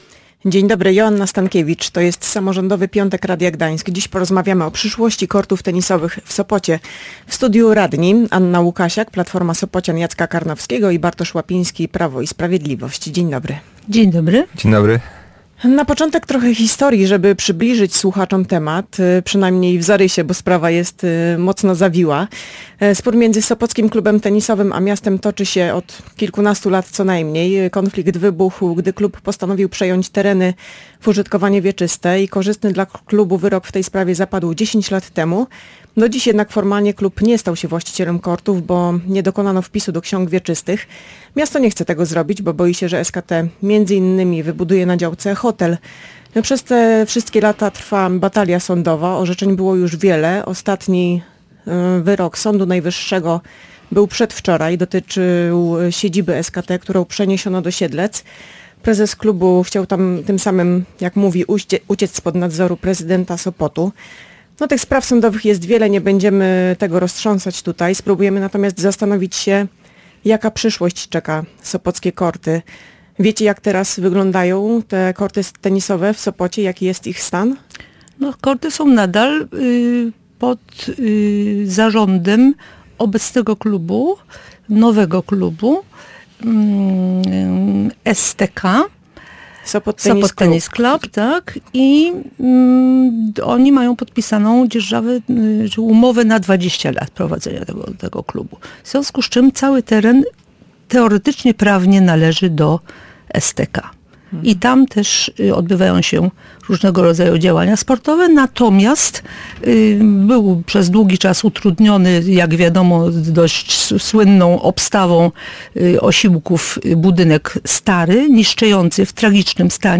Gośćmi audycji Samorządowy Piątek byli radni: Anna Łukasiak z Platformy Sopocian Jacka Karnowskiego i Bartosz Łapiński z PiS.